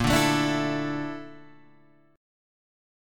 BbM#11 chord